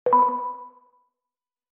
Звук неизвестного голосового помощника